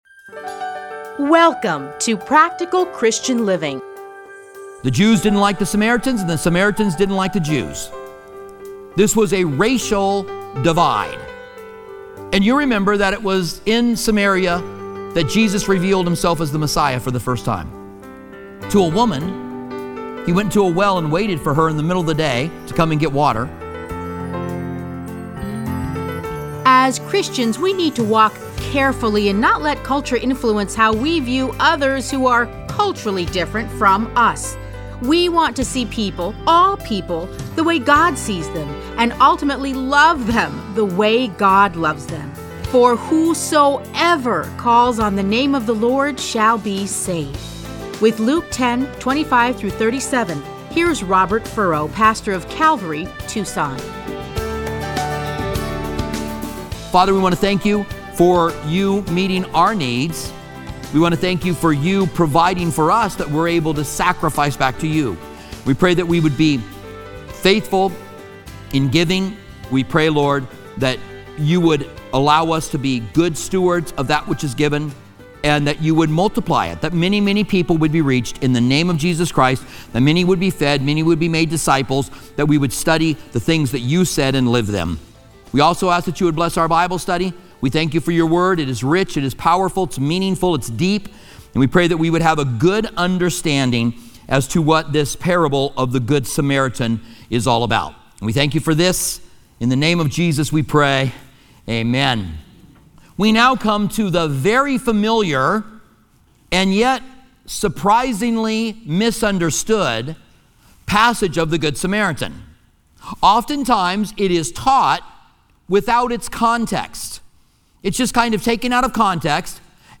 Listen to a teaching from Luke Luke 10:25-37.